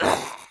mifn_damage_v.wav